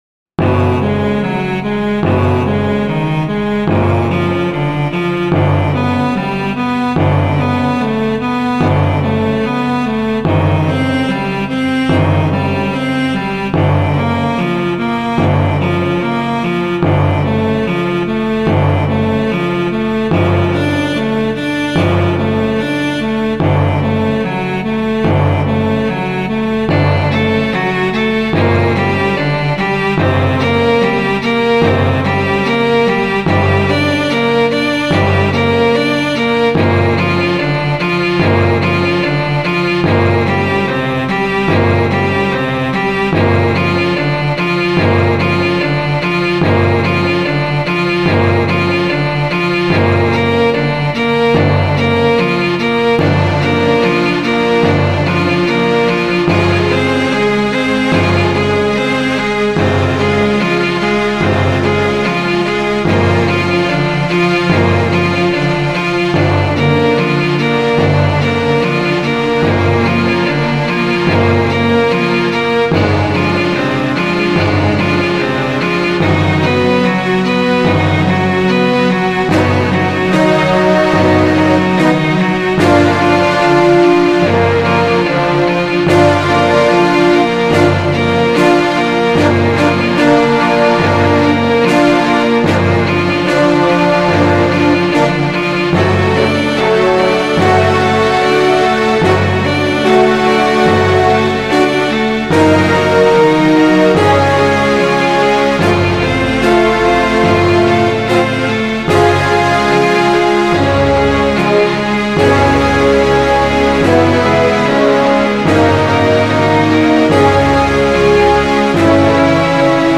So machte ich mich Anfang 2012 ans Werk. Auf der Basis einer sagenartigen Geschichte mit dem Titel Der Glockenhain entstand am Ende eine Ballett-Suite mit 11 Einzelstücken bei klassischer Orchesterbesetzung, konzipiert für klassisches Ballett.